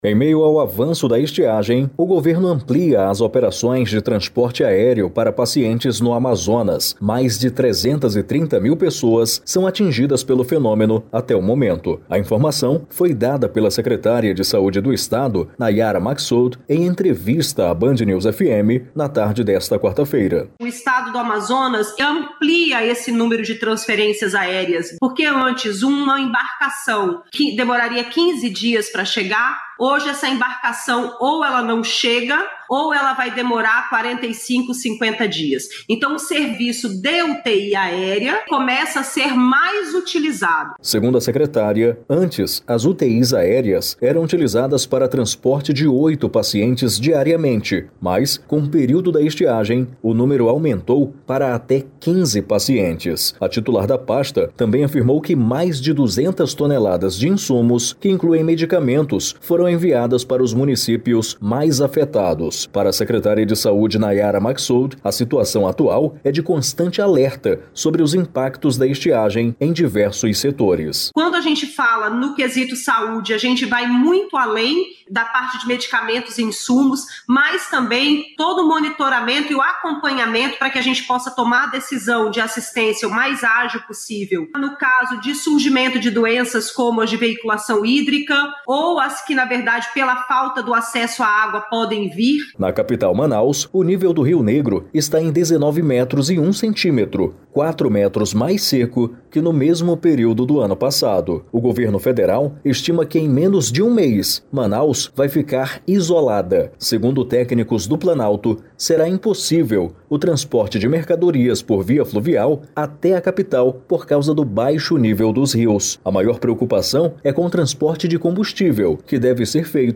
A informação foi dada pela secretária de saúde do estado, Nayara Maksoud, em entrevista à BandNews FM, na tarde desta quarta-feira.